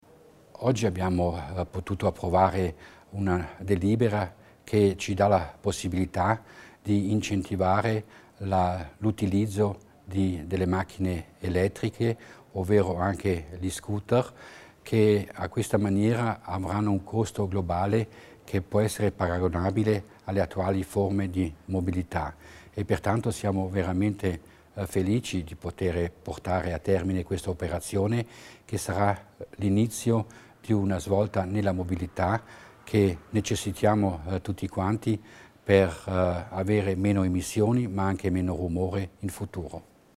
L'Assessore Mussner spiega le strategie per una mobilità pulita